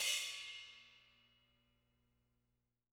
R_B Splash A 02 - Close.wav